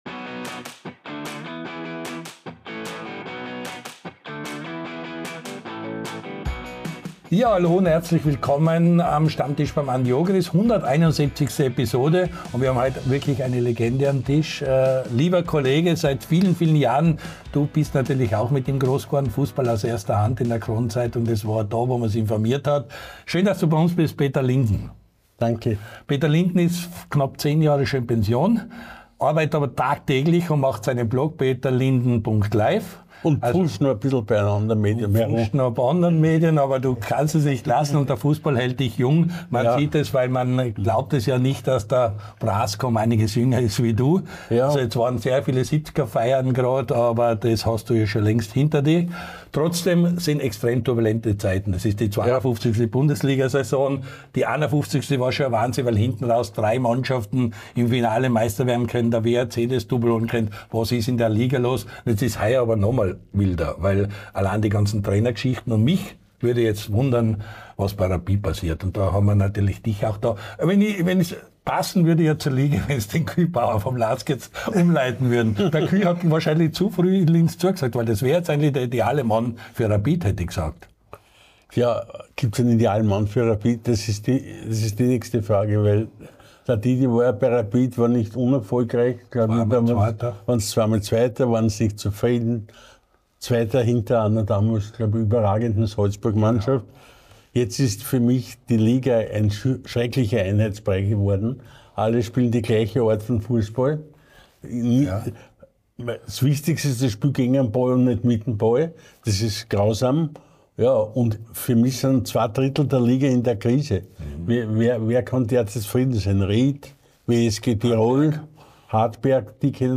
Der LAOLA1-Kulttalk mit Andy Ogris